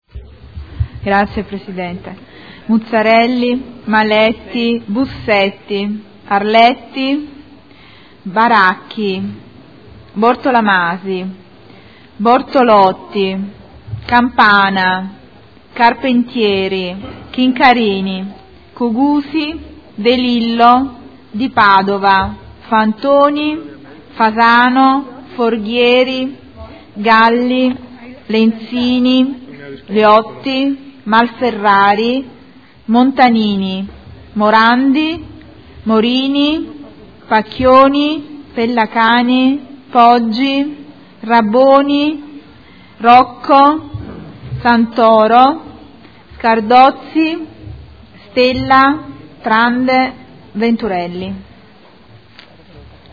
Segretario Generale — Sito Audio Consiglio Comunale
Seduta del 28/05/2015. Appello